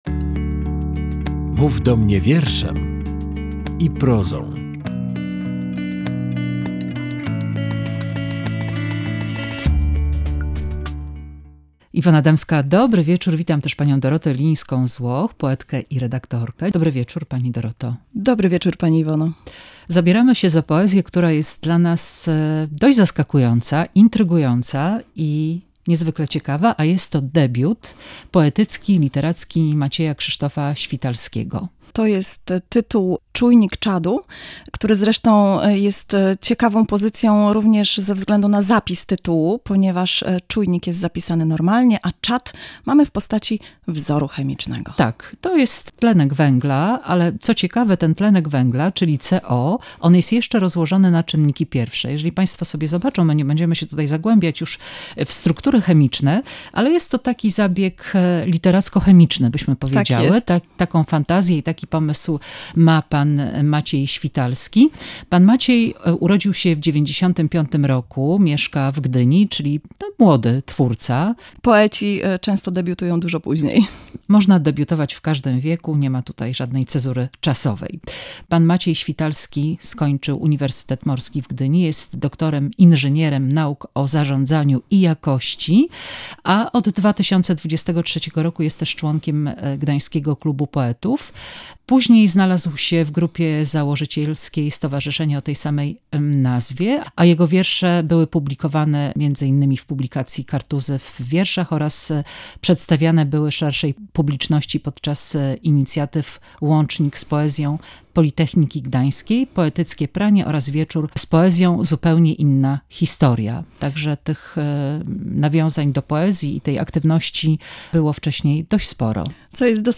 Rozmowa